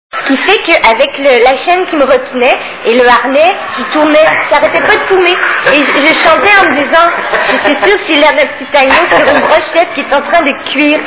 STARMANIA...Interview
( Casino de Paris, Hall d'entrée, 06/02/2000 )